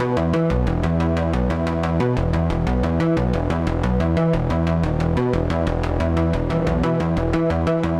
Index of /musicradar/dystopian-drone-samples/Droney Arps/90bpm
DD_DroneyArp1_90-E.wav